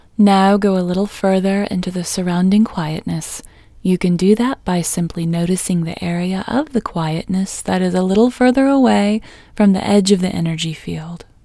OUT Technique Female English 8